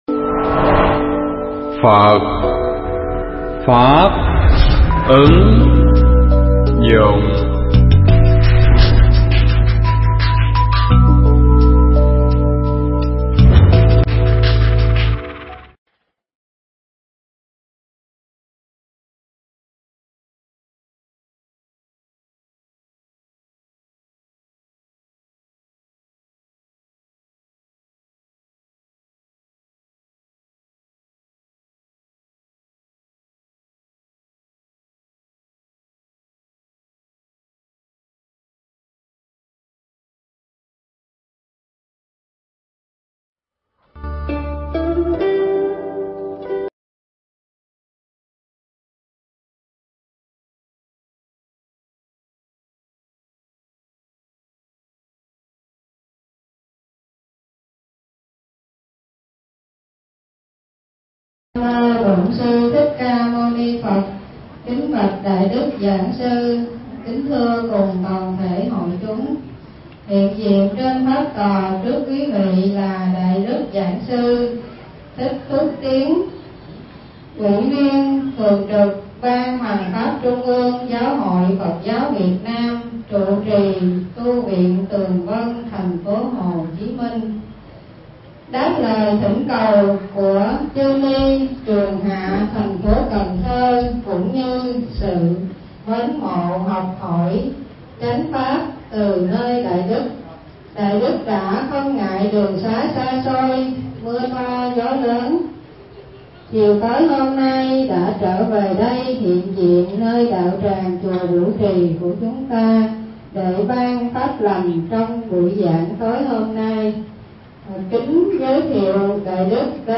Tải mp3 Pháp thoại Xuất Gia Để Làm Gì? english sub